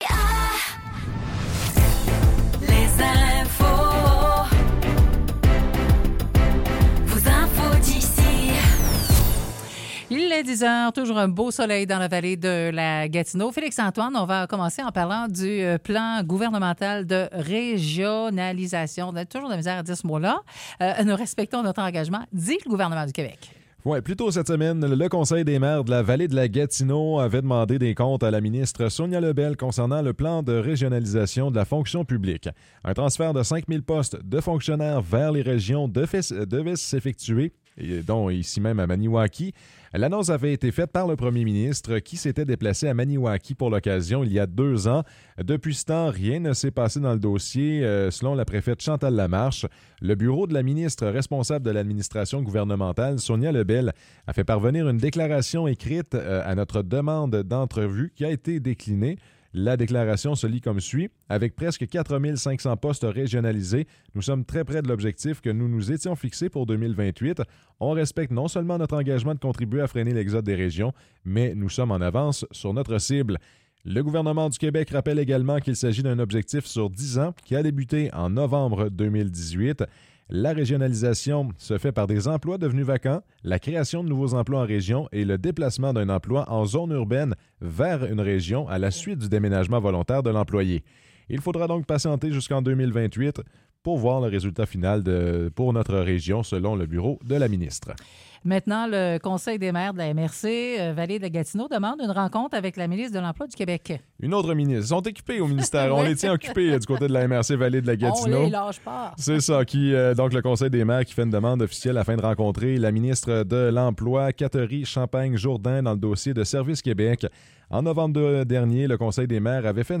Nouvelles locales - 12 janvier 2024 - 10 h